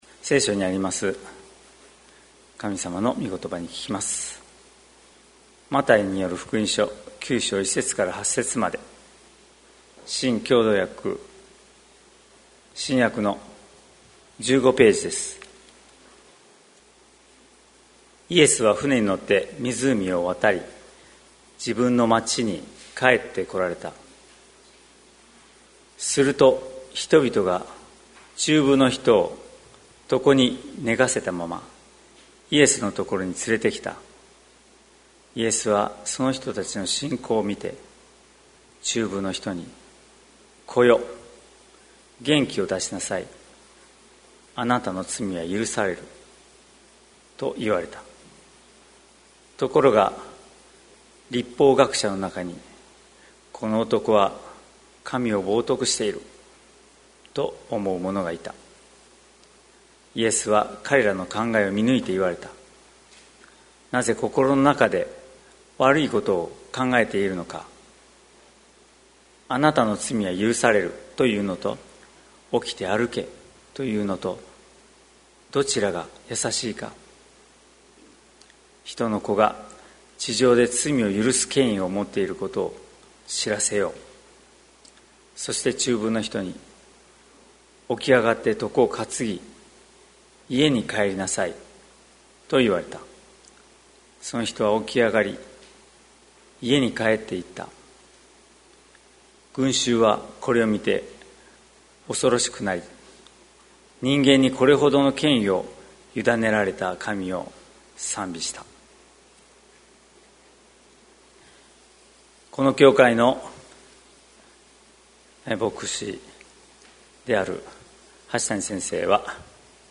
2021年08月22日朝の礼拝「どちらがやさしいか」関キリスト教会
説教アーカイブ。